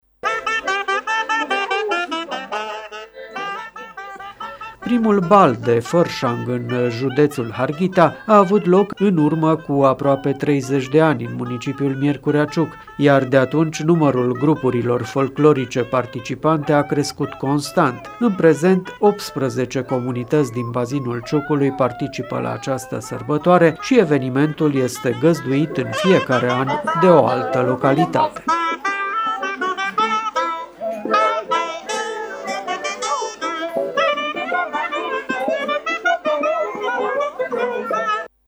După deschiderea oficială a evenimentului, grupurile folclorice din cele 15 localități au mers pe străzile din Sândominic, pe trasee diferite, şi au prezentat câte o mică scenetă, în care s-au luat în râs proastele obiceiuri şi au avut loc ritualuri prin care oamenii îşi luau rămas de la o păpuşă care este simbolul viciilor şi care la final a fost arsă, pentru ca spiritul curat să renască.
Evident bucatele au fost stropite cu palincă şi vin și totul a fost însoțit de dans și voie bună.
Insert-muzica.mp3